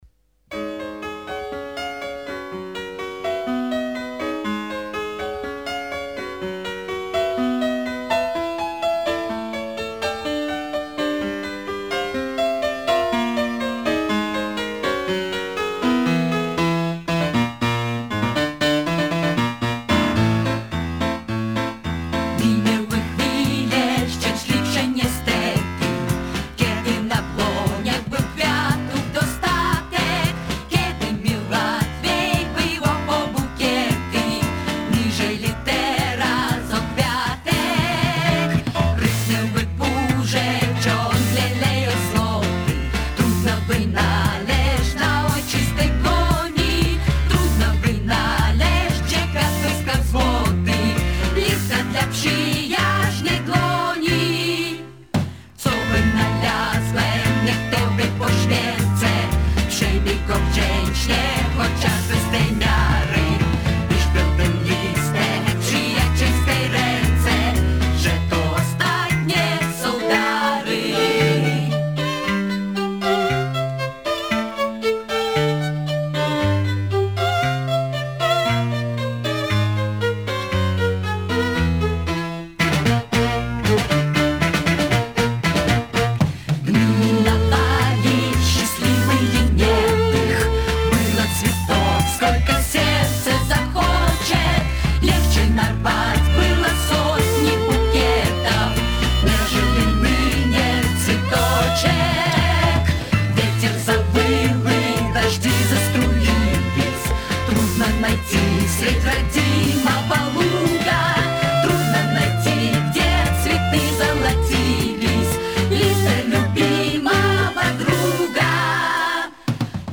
он выпускает концептуальный альбом в стиле арт-рок.